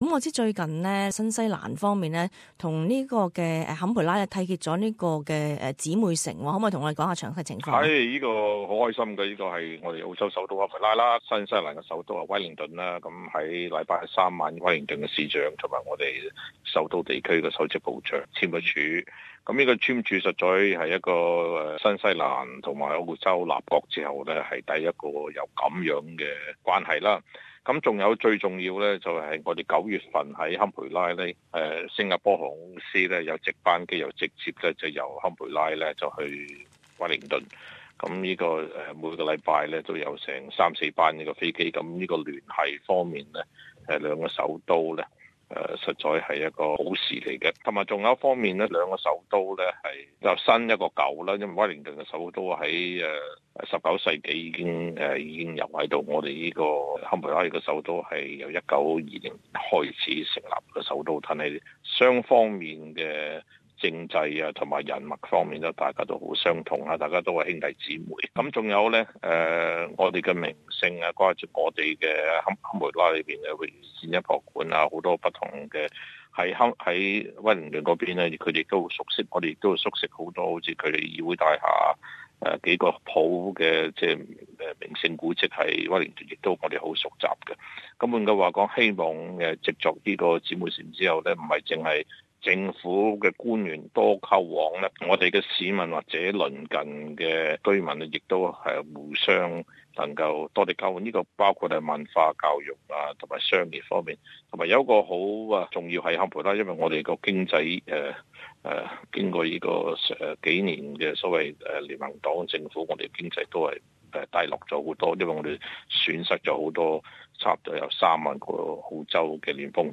Canberra correspondence: